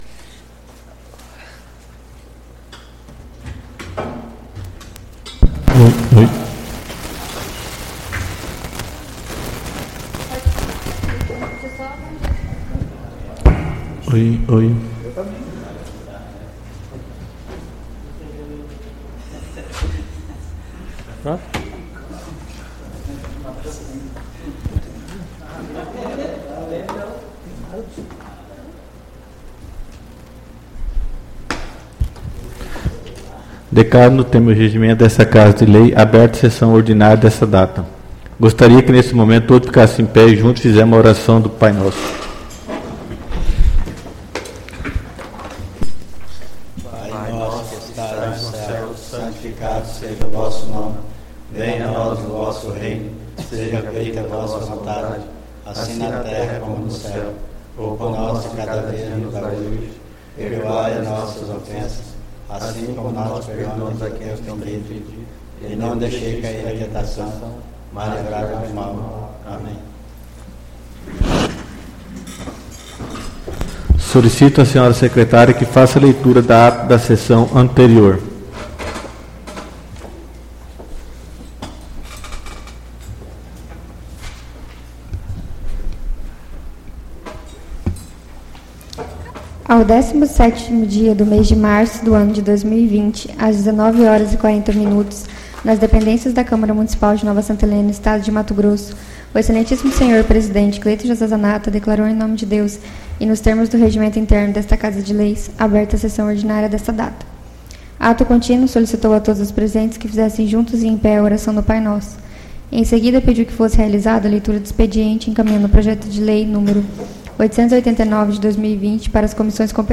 SESSÃO ORDINÁRIA DO DIA 07/04/2020